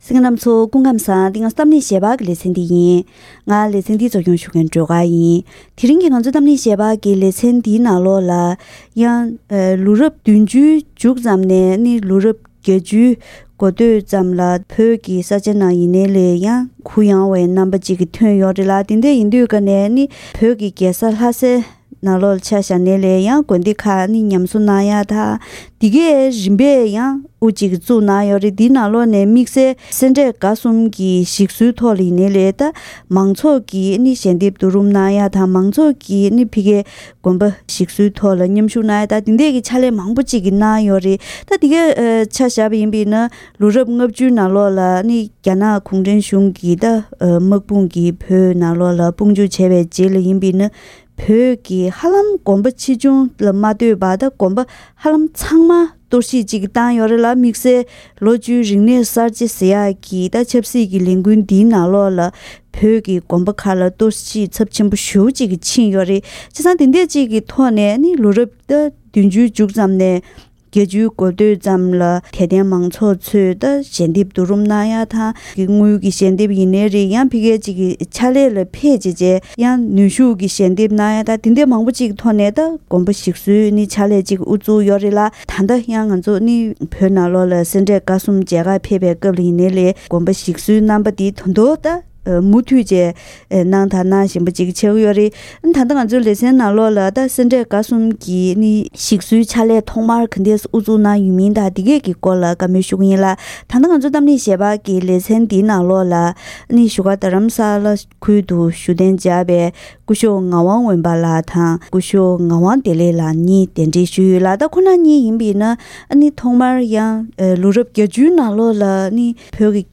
ཐེངས་འདིའི་གཏམ་གླེང་ལེ་ཚན་ནང་། རྒྱ་ནག་གཞུང་གིས་བོད་ལ་བཙན་འཛུལ་བྱས་པའི་རྗེས་བོད་ཀྱི་དགོན་སྡེ་མང་པོར་གཏོར་བཤིག་ཚབས་ཆེན་བཏང་ཡོད་པ་དང་། ལོ་རབས་བརྒྱད་བཅུའི་ནང་དམངས་ཁྲོད་ནས་སེ་འབྲས་དགའ་གསུམ་ཉམས་གསོ་ཇི་ལྟར་གནང་ཡོད་པའི་སྐོར་ལ་ཉམས་གསོའི་ཐོག་དངོས་སུ་མཉམ་ཞུགས་གནང་མཁན་མི་སྣ་ཁག་ཅིག་ལྷན་གླེང་མོལ་ཞུས་པ་ཞིག་གསན་རོགས་གནང་།